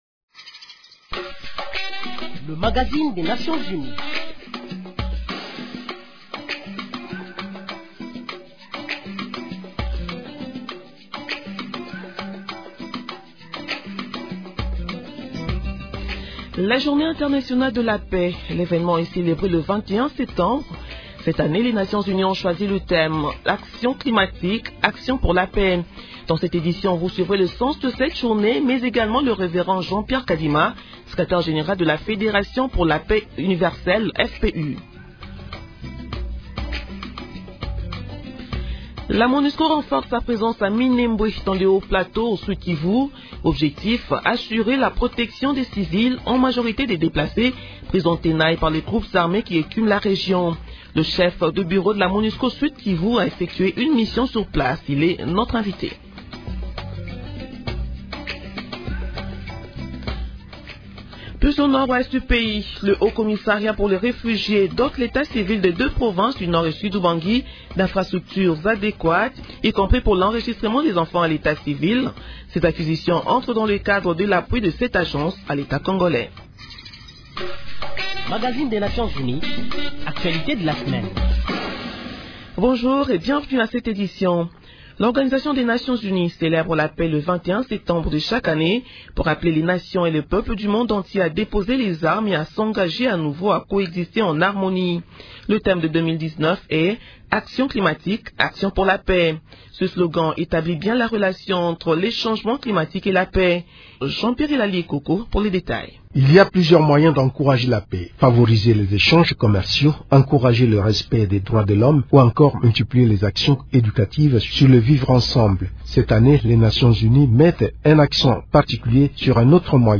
Décryptage En marge de la célébration de la Journée internationale de la paix